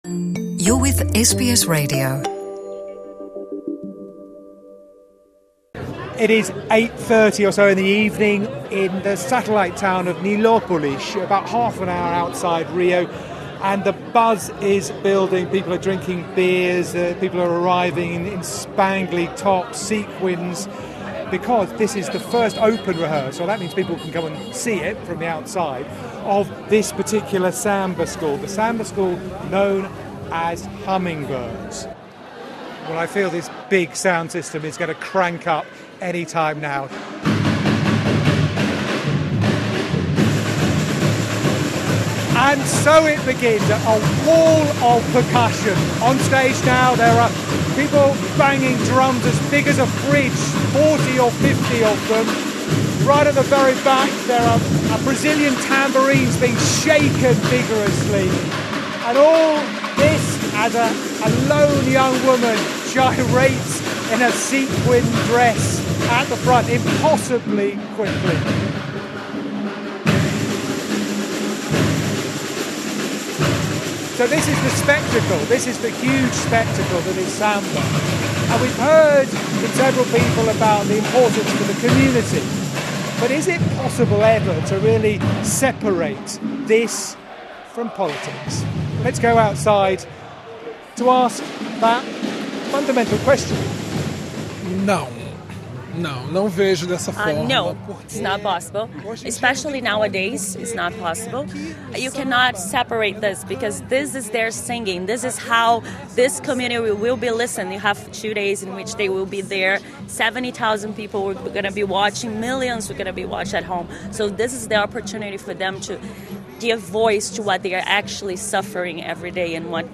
The election of Luis Inacio Lula da Silva as president - two decades after he first came to power - has split the country. So how does Brazil even begin to unite around its new president elect? We find out at a samba school preparing for the Rio carnival, which - along with football - is as close to a national cultural event as you can get.